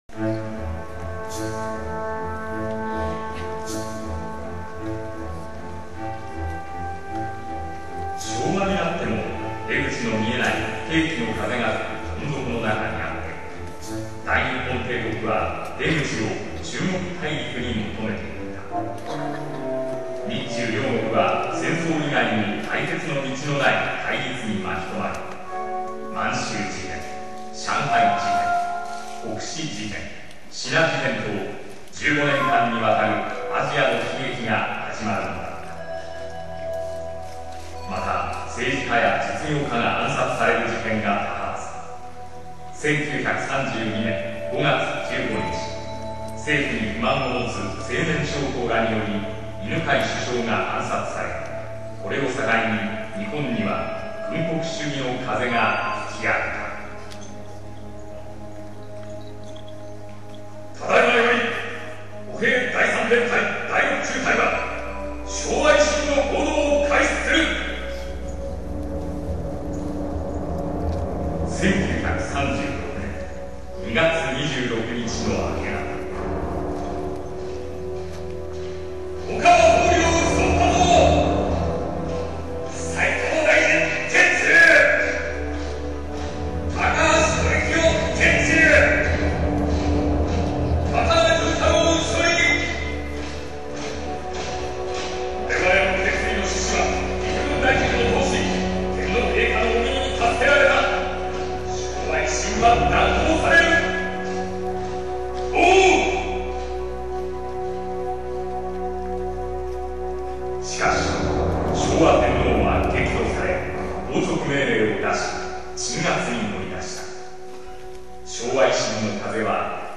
ジ ョ イ ン ト リ サ イ タ ル
平成十九年八月二十六日 (日)　於：尼崎アルカイックホール